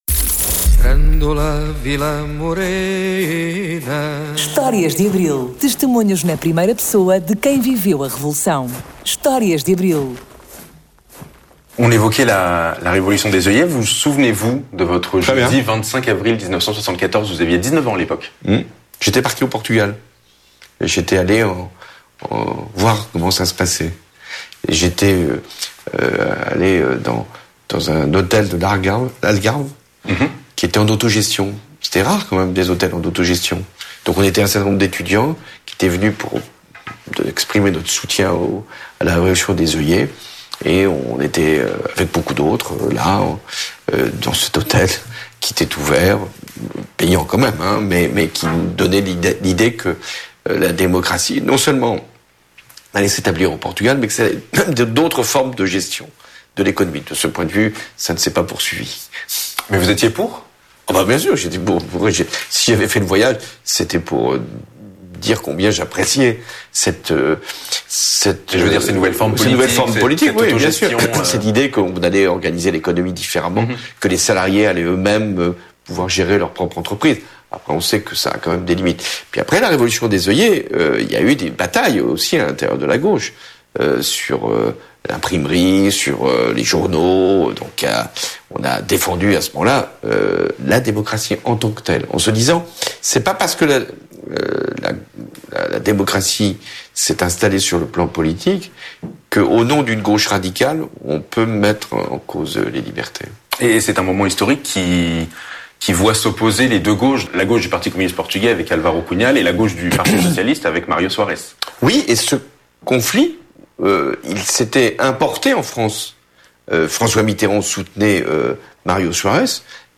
Testemunhos na primeira pessoa de quem viveu o 25 de Abril de 1974.
François Hollande, Presidente da República Francesa de 2012 a 2017, deputado do PS (testemunho em francês):